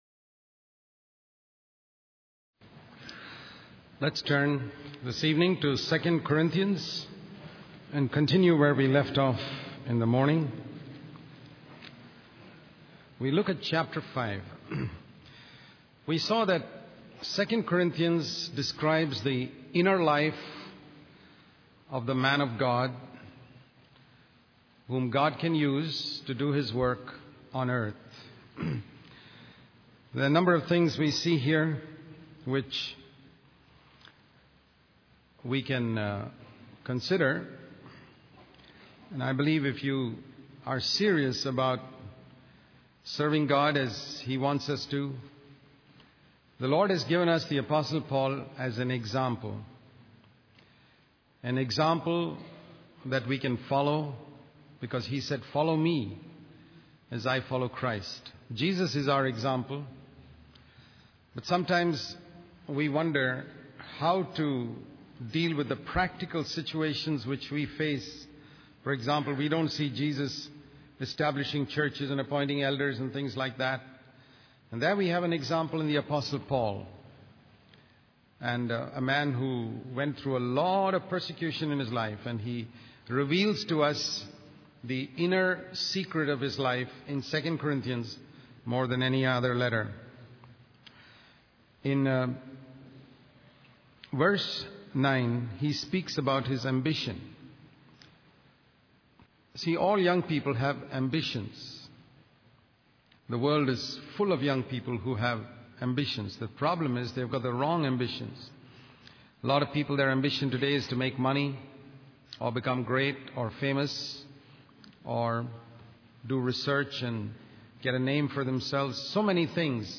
In this sermon, the speaker focuses on the qualities and characteristics of the apostle Paul as a servant of God. He emphasizes that Paul was called by God and had a deep love for those he served.